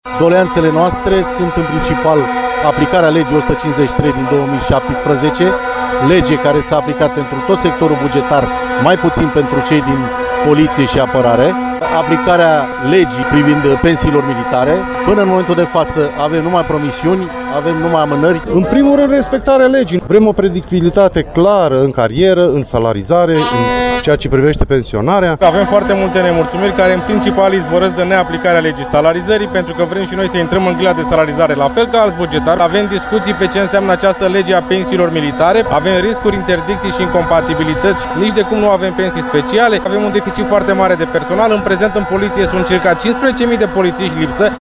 06-protest-voxuri-17.mp3